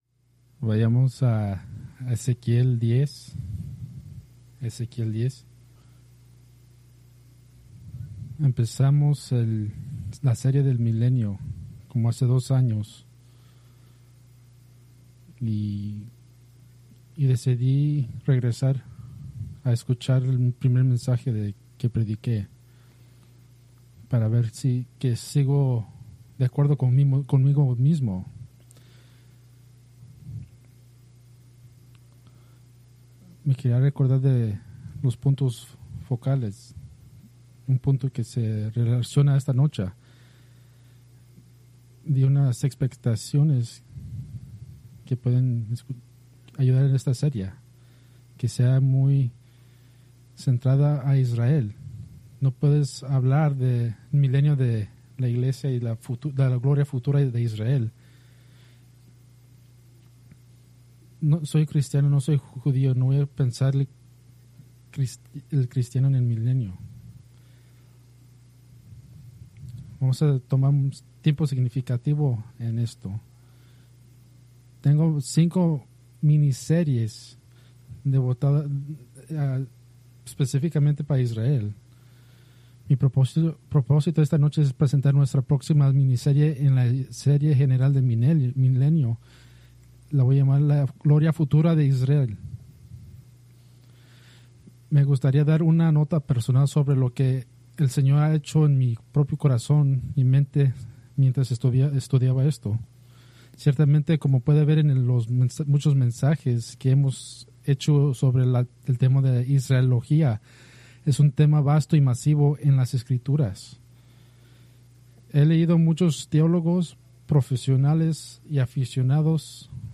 Preached October 5, 2025 from Escrituras seleccionadas